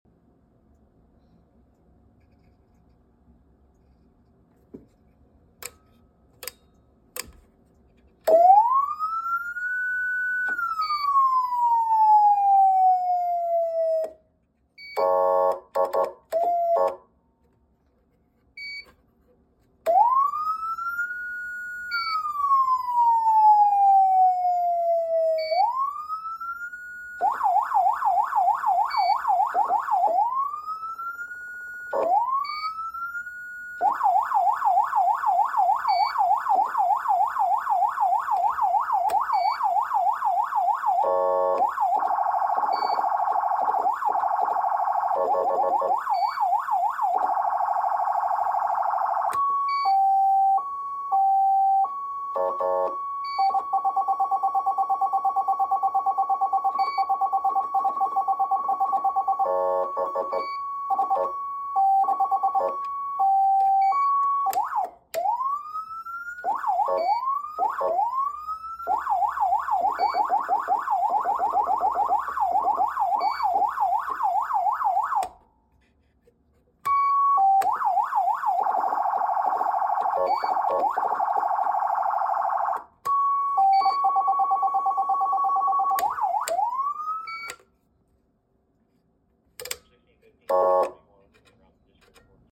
Code 3 Mastercom (3892L6) Siren Sound Effects Free Download